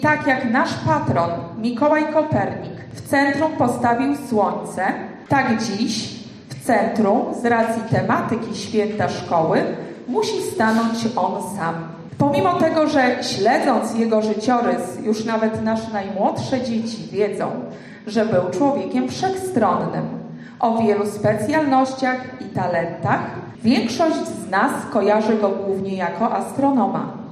Tak Szkoła Podstawowa w Goworowie obchodziła wczoraj swój złoty jubileusz 50-lecia nadania imienia Mikołaja Kopernika.